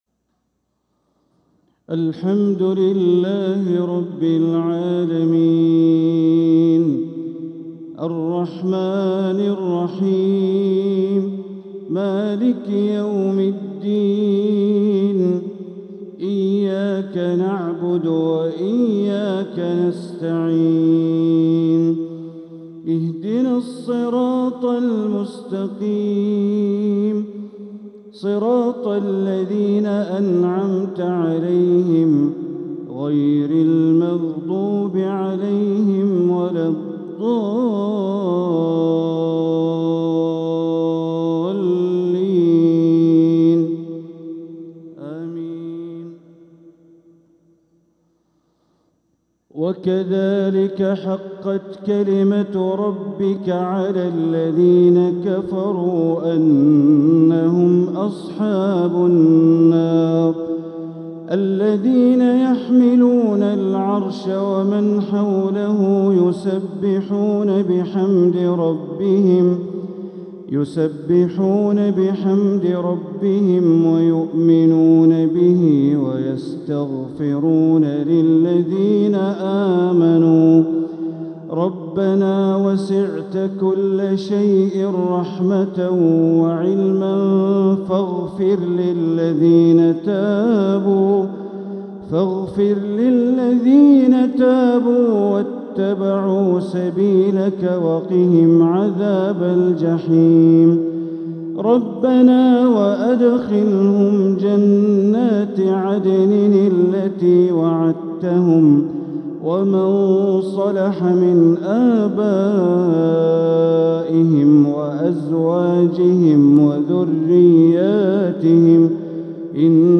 تلاوة من سورة غافر عشاء الجمعة ٣٠محرم١٤٤٧ > 1447هـ > الفروض - تلاوات بندر بليلة